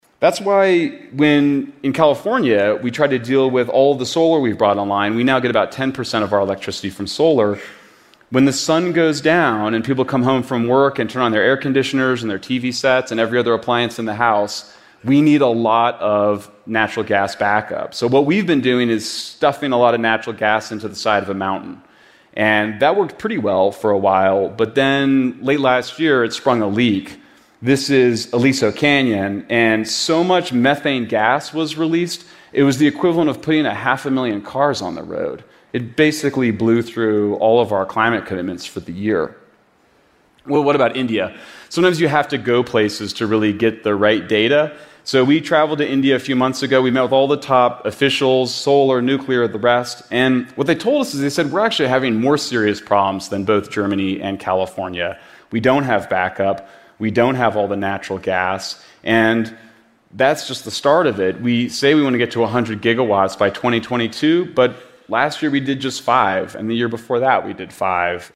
TED演讲:恐惧核能是怎样伤害环境的(4) 听力文件下载—在线英语听力室